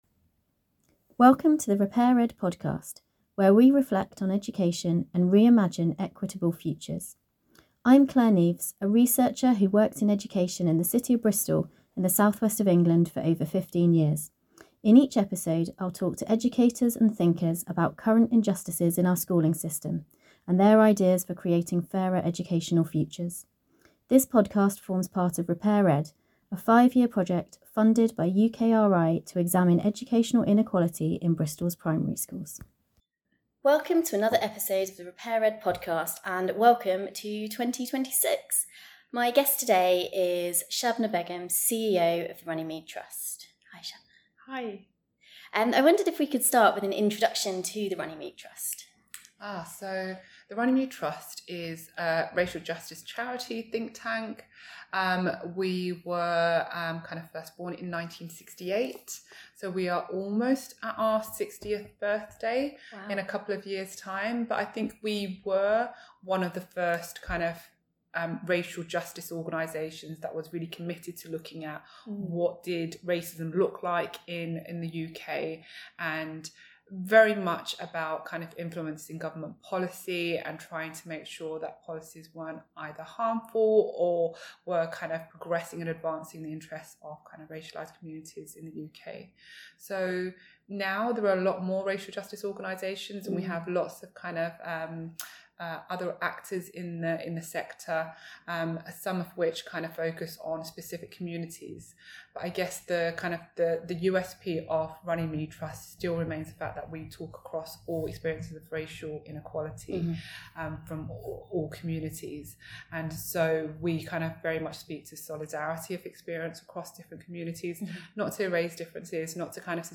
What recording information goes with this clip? In Hackney (listen out for the Overground trains!) they discuss curriculum, reparatory justice and how the wider political climate impacts schools.